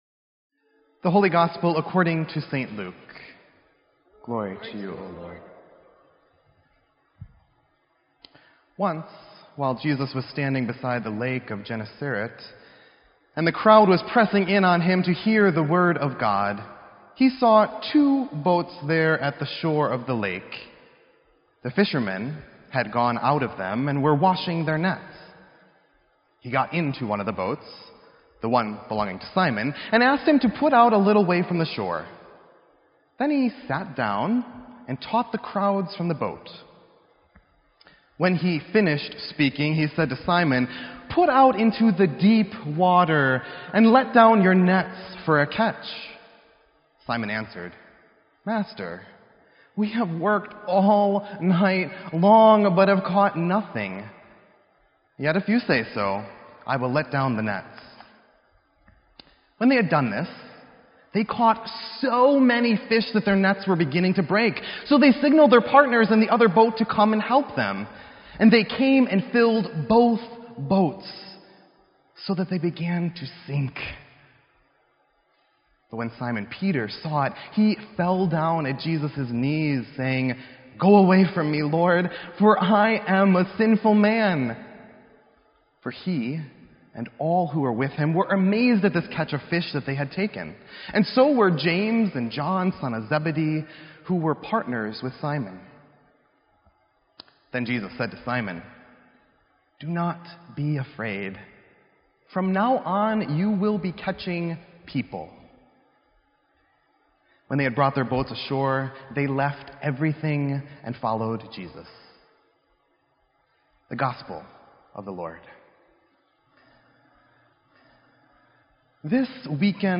Sermon_9_4_16.mp3